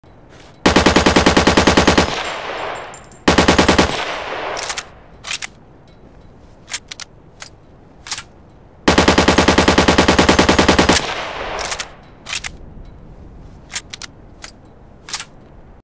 جلوه های صوتی
دانلود صدای ژ3 از ساعد نیوز با لینک مستقیم و کیفیت بالا